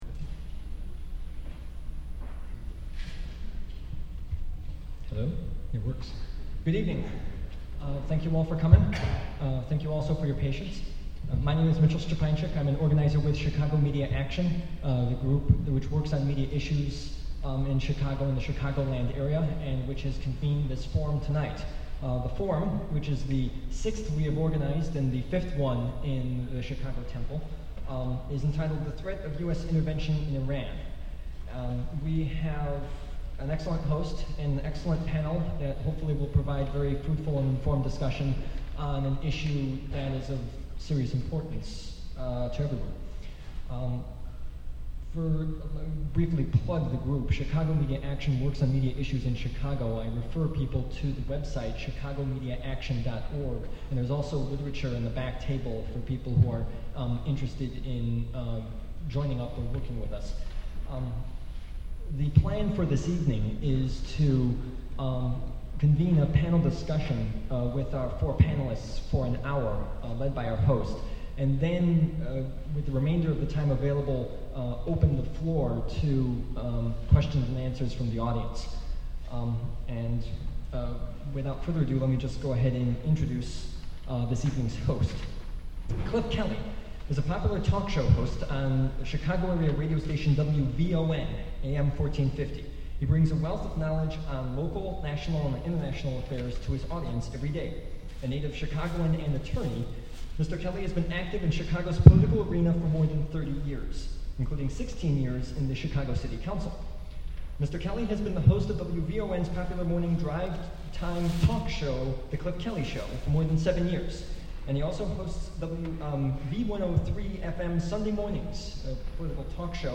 Download the complete audio of the forum.